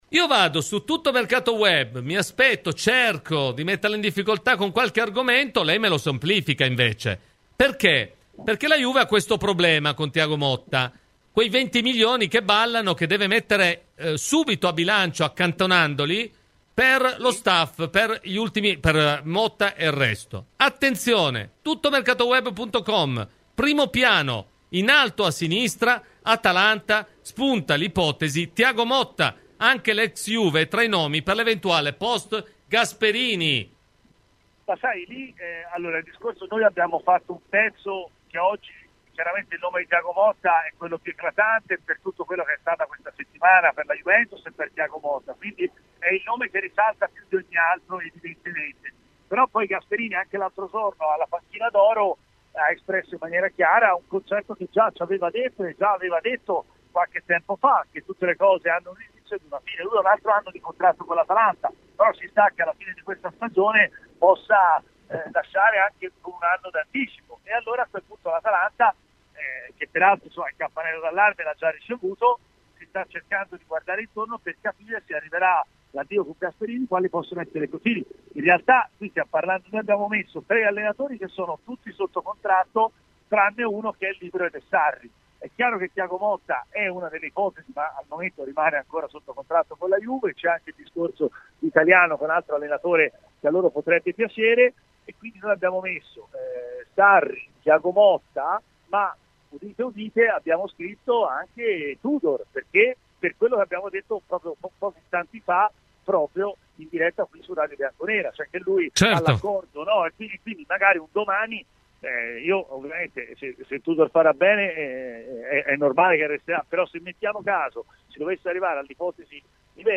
Ospite di “Cose di Calcio” su Radio Bianconera